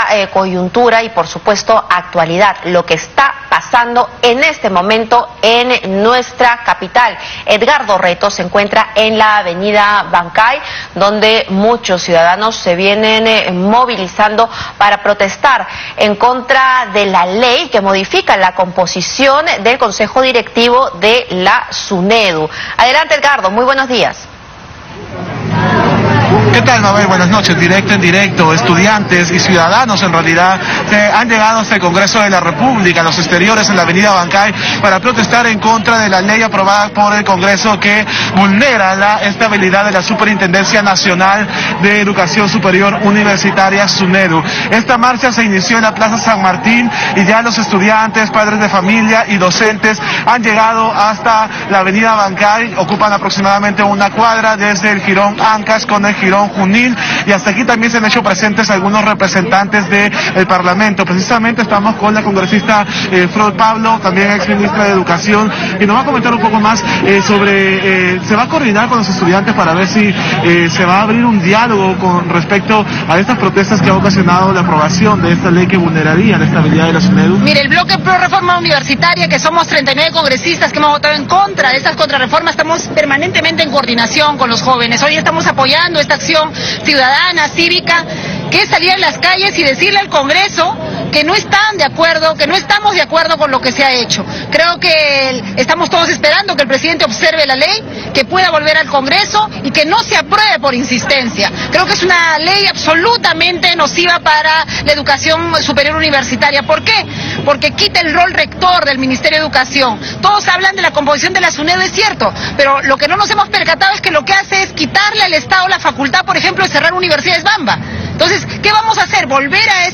Enlace en vivo.